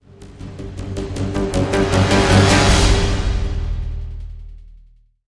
Music Transition; Intense Action Drums Fade In And Out.